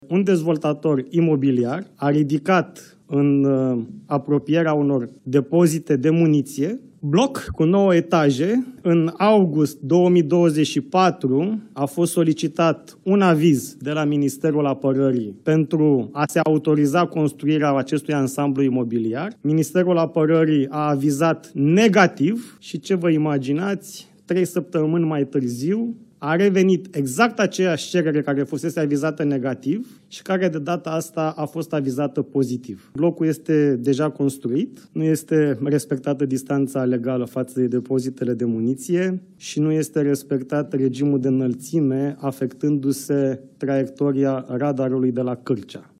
Ministrul Apărării, Radu Miruță: „Blocul este deja construit, nu este respectată distanța legală față de depozitele de muniție”